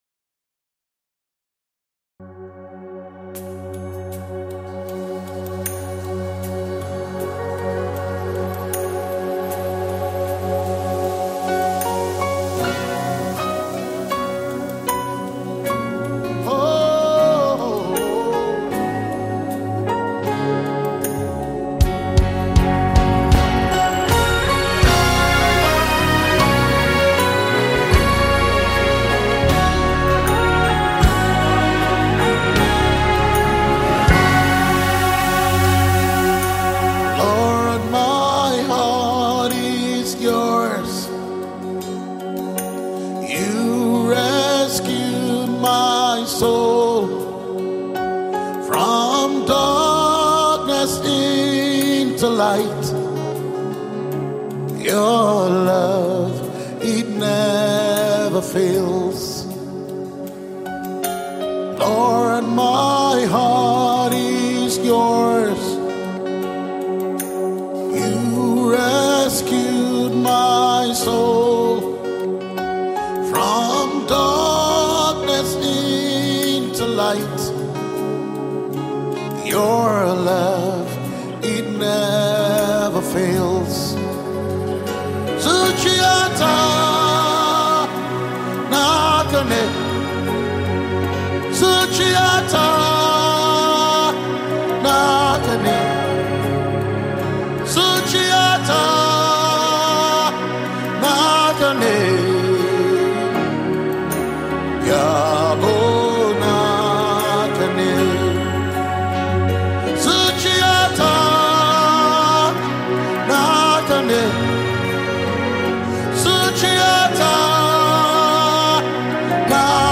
Nigerian gospel
was recorded live in the beautiful city of Abuja Nigeria.